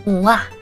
Worms speechbanks
Jump1.wav